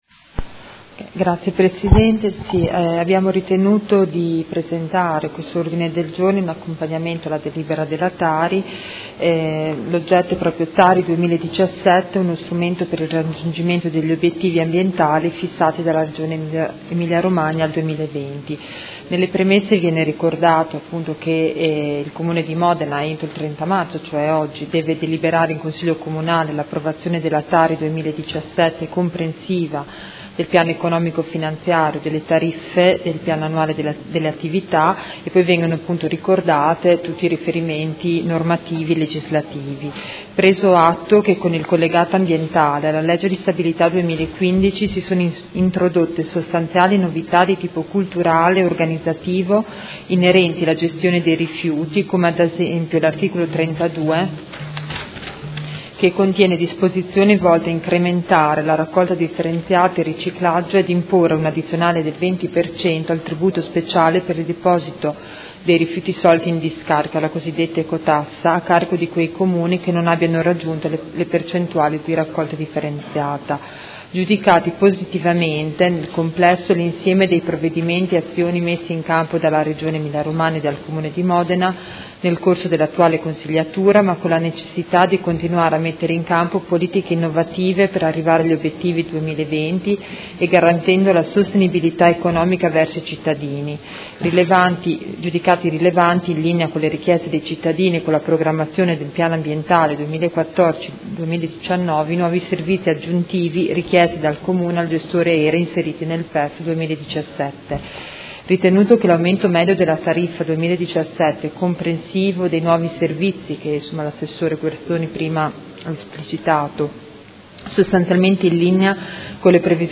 Grazia Baracchi — Sito Audio Consiglio Comunale
Seduta del 30/03/2017. Ordine del Giorno presentato dai Consiglieri Arletti, Baracchi, Bortolamasi, Forghieri, Lentini, Carpentieri, Pacchioni, Liotti, De Lillo, Venturelli (PD), Malferrari, Cugusi e Rocco (Art.1-MDP) avente per oggetto: TARI 2017 uno strumento per il raggiungimento degli obiettivi ambientali fissati dalla Regione Emilia-Romagna al 2020